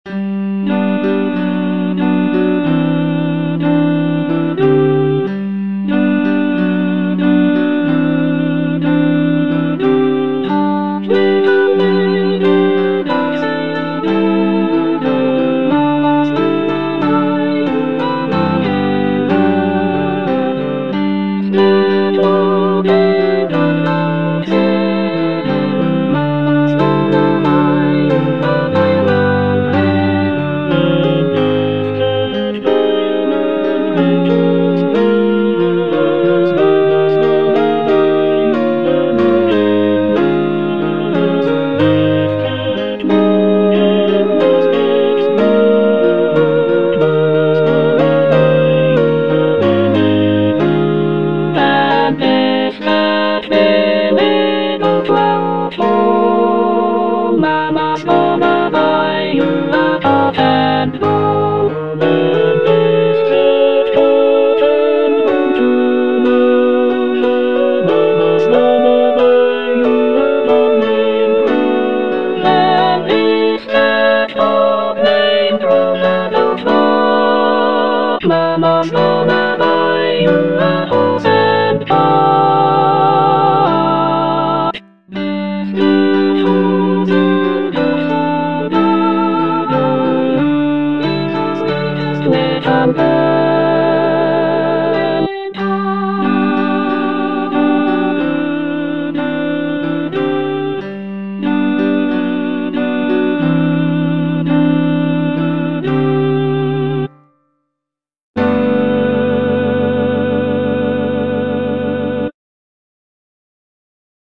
(alto I) (Emphasised voice and other voices) Ads stop